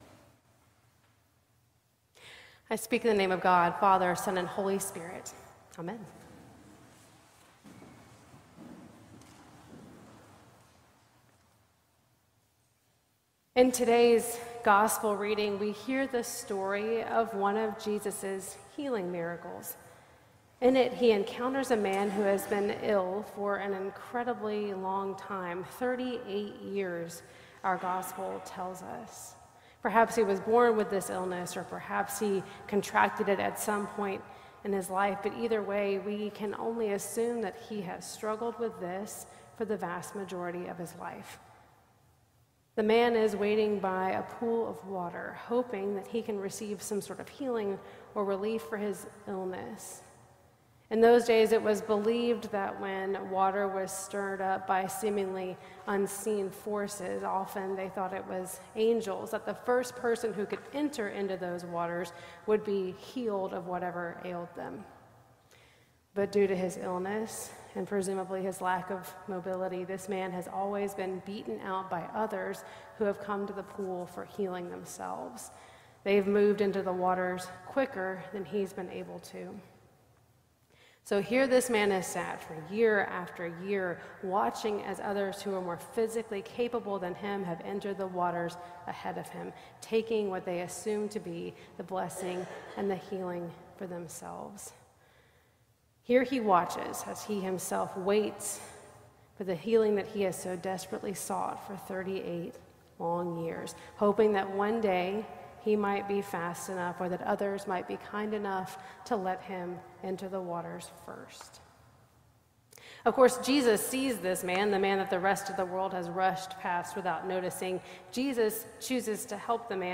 Sermon - March 2nd, 2025 - Last Sunday after the Epiphany